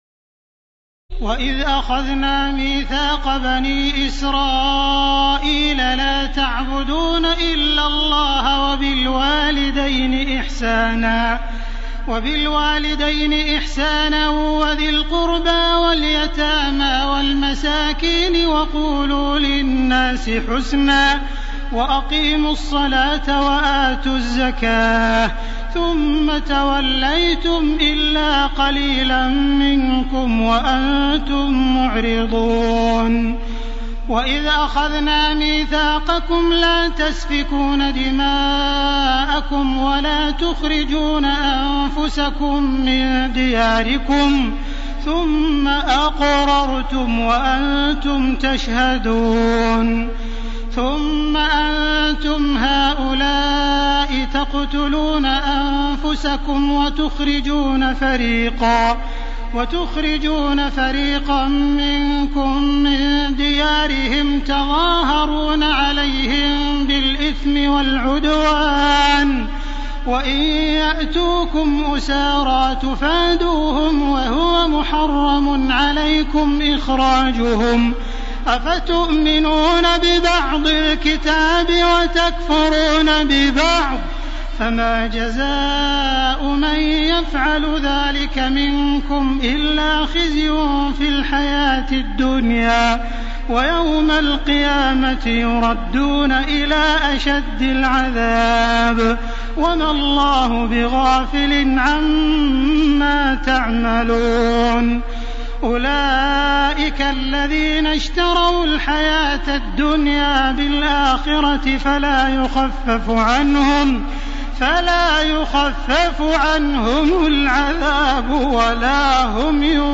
تراويح الليلة الأولى رمضان 1431هـ من سورة البقرة (83-141) Taraweeh 1st night Ramadan 1431 H from Surah Al-Baqara > تراويح الحرم المكي عام 1431 🕋 > التراويح - تلاوات الحرمين